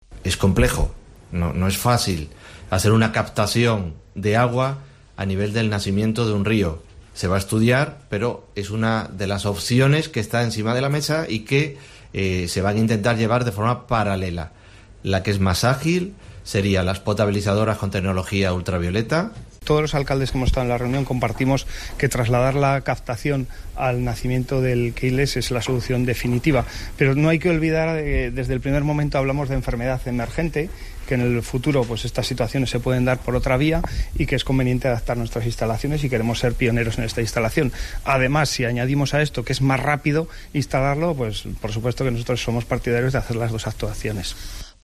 El consejero de Sanidad y el alcalde de Tarazona valoran las propuestas al problema del río Queiles.